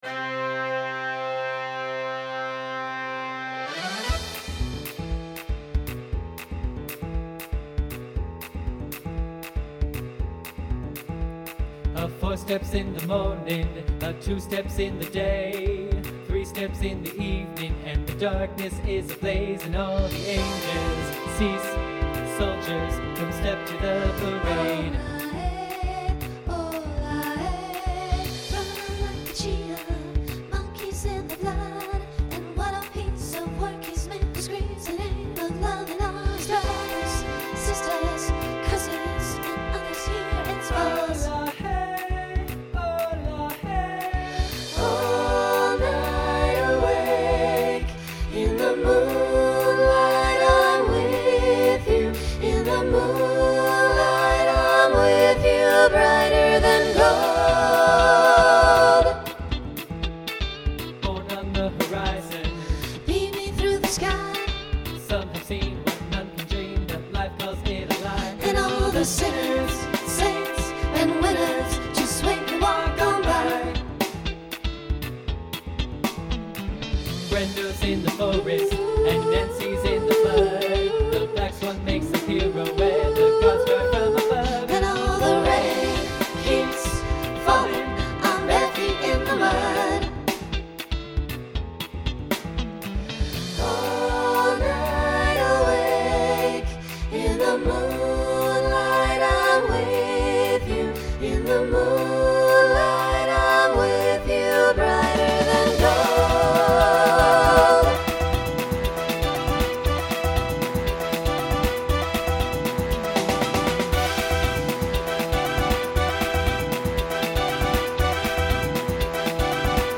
SATB Instrumental combo
Rock
Mid-tempo